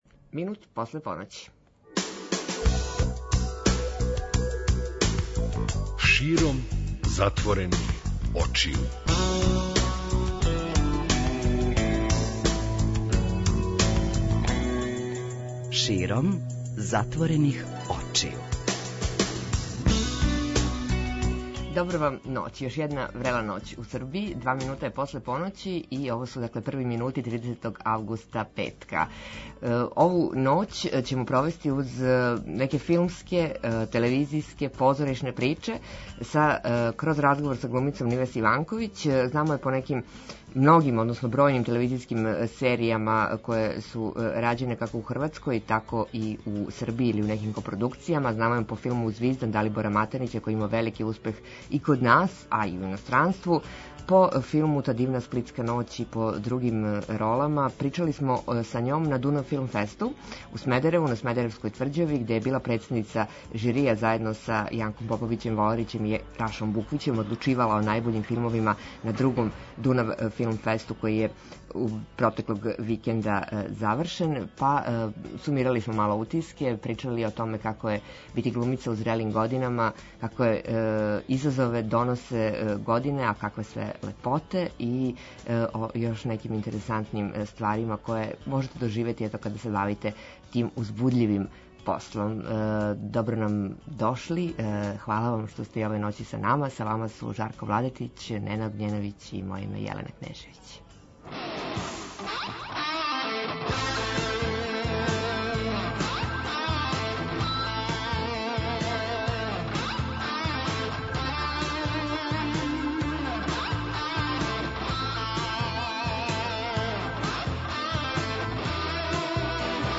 Интервју: Нивес Иванковић, глумица